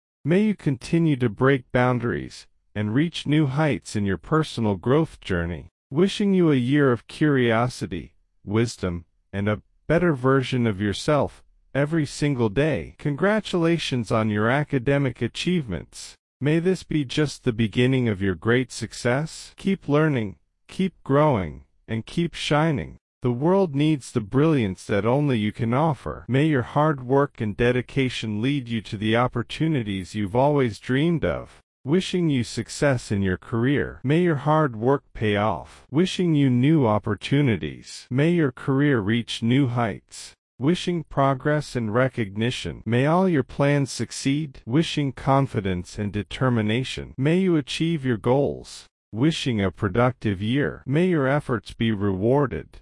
🎧 Hãy nhấn vào link MP3 bên dưới để nghe và luyện theo ngữ điệu của người bản xứ.